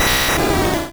Cri de Dracolosse dans Pokémon Rouge et Bleu.